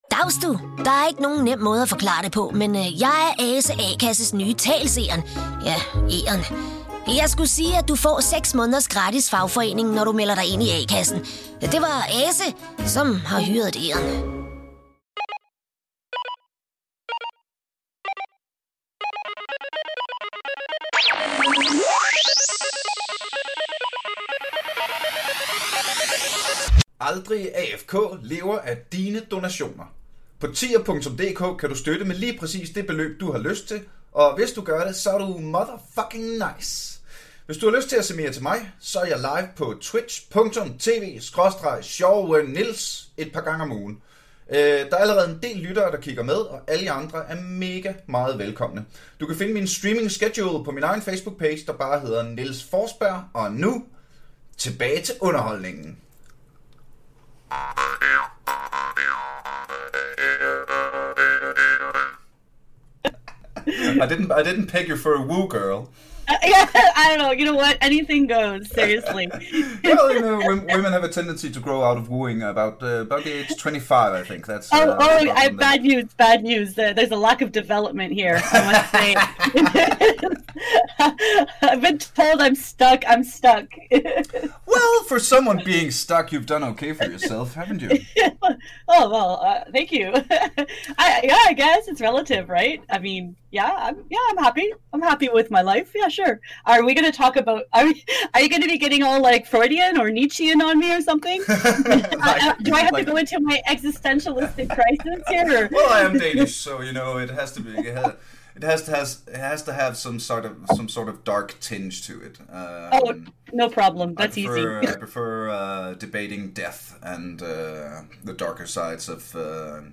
A conversational look straight in to the nuts and bolts of making music for AAA-games... the deadlines, the creative process and the differences between working for the small and the big guns!